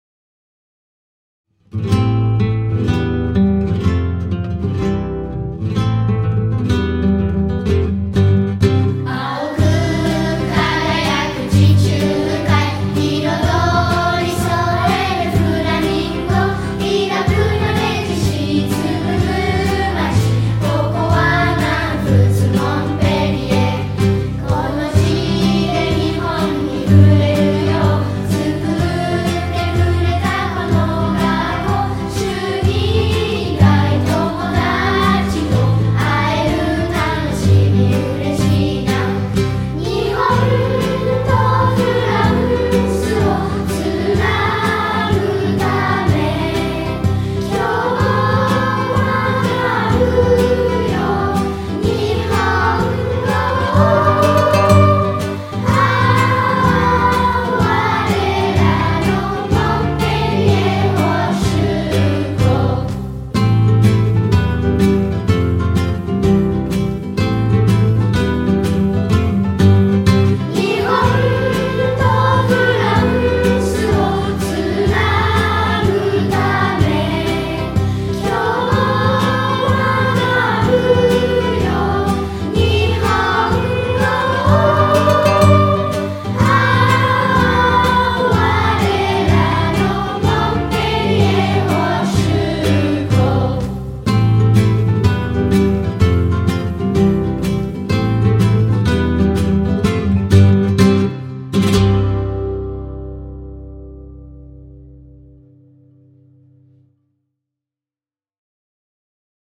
作：串崎 晴美
作曲：Lucas Gitano Family
軽快なギターの伴奏で、口ずさみたくなるメロディーが加わり、我が校の校歌ができました。
・ギターのメロディーがかっこよくて気に入っています。
・メロディーがきれいだと思います。明るくて、耳に残るメロディーです。